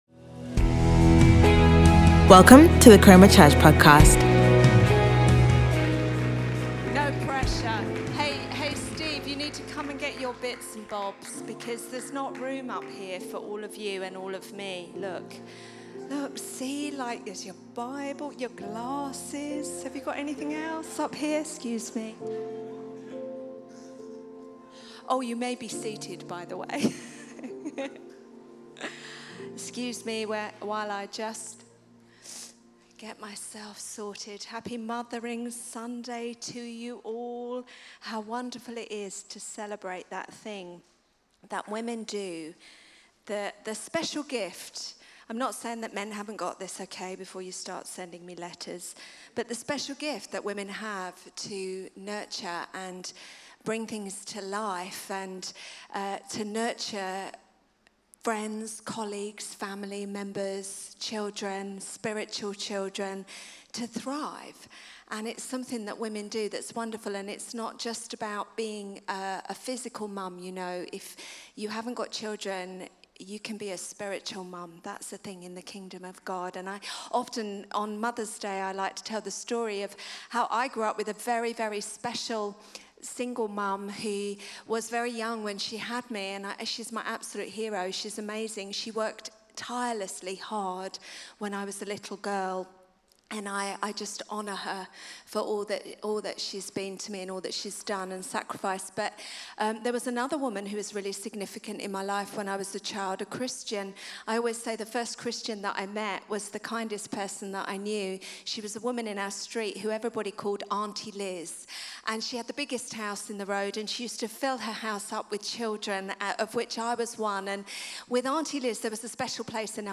Sunday Sermon We Sow For More Of Him